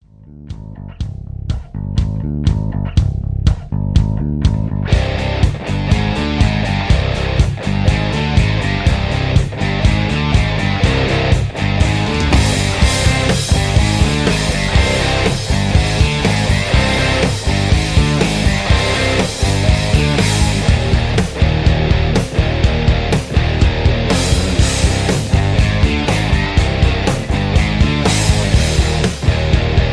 Tags: rock , r and b , middle of the road , metal